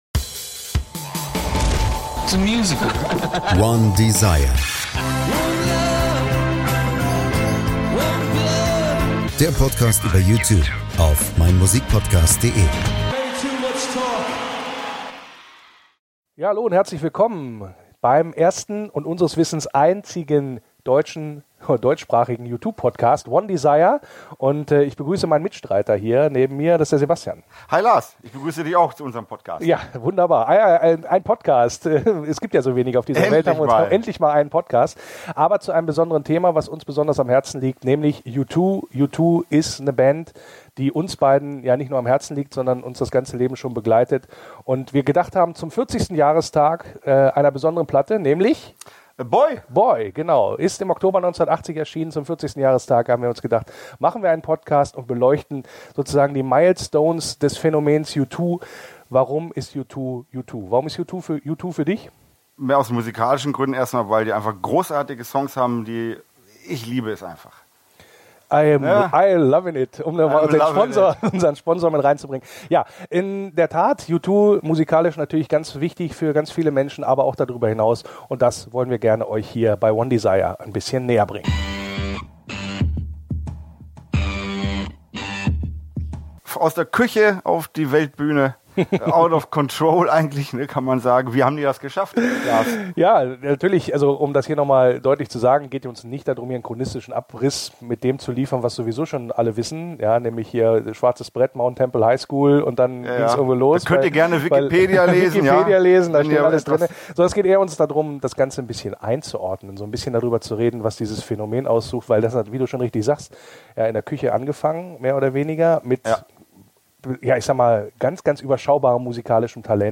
Soundbite Bei one:desire wird auch ganz praktisch zur Gitarre gegriffen und gezeigt, wie sich U2 gerade in der Anfangsphase vom Mainstream in der Musikwelt abhoben, wie der U2-Sound entstand und was sie grundsätzlich von einer Punkband unterschied, die sie eigentlich gern sein wollten.